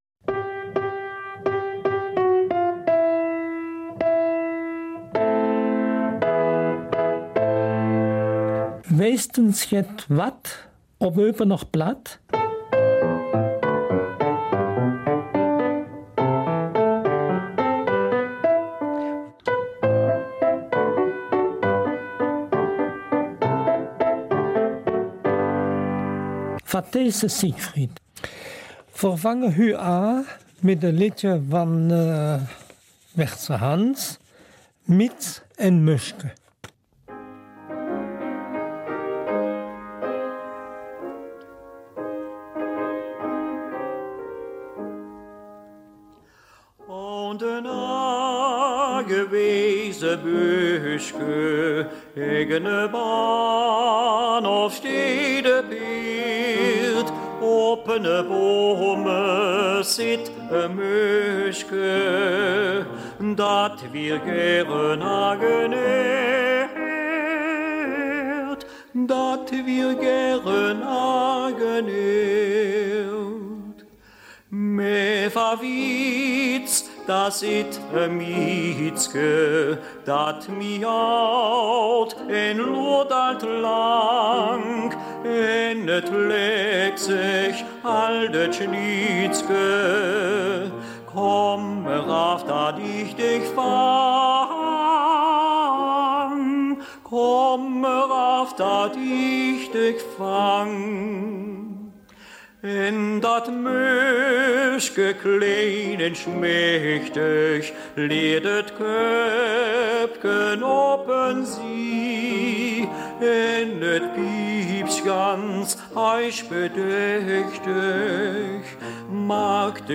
Eupener Mundart - 9. Juli